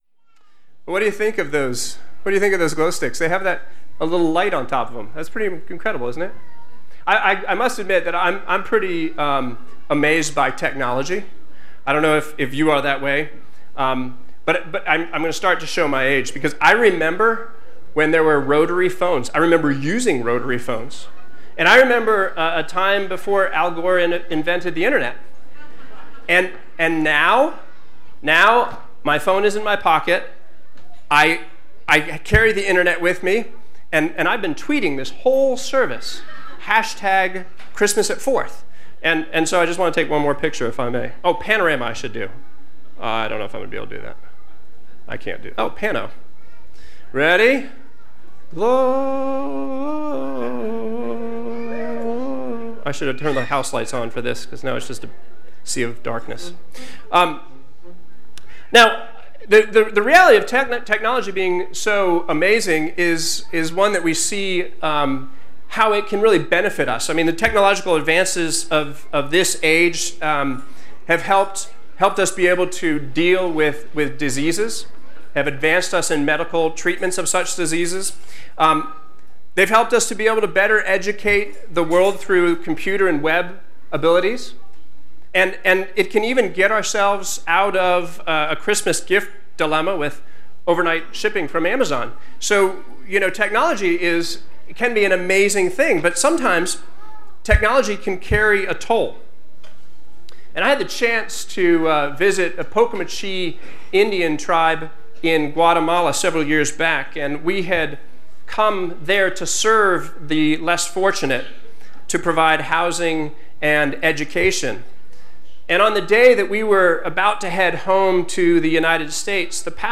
Family Christmas Eve
Evening Service